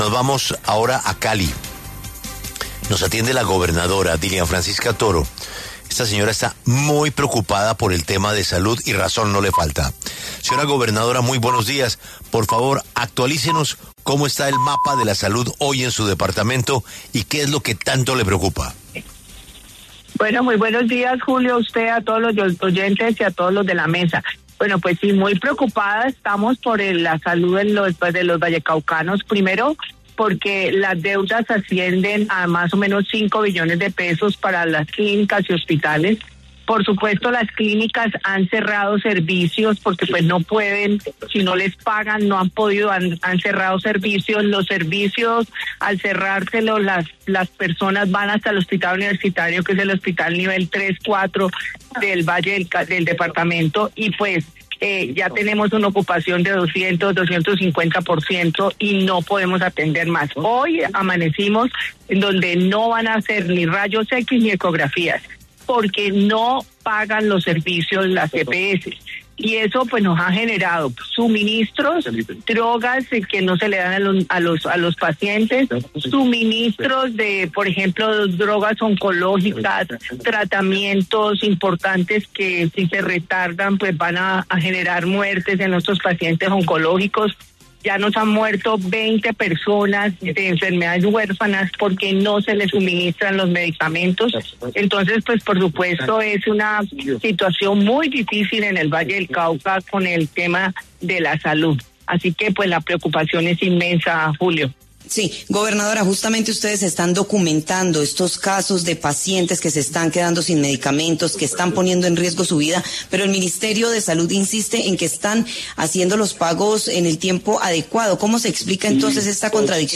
En entrevista con W Radio, la gobernadora del Valle del Cauca, Dilian Francisca Toro, lanzó una nueva advertencia sobre la grave crisis del sistema de salud en el departamento.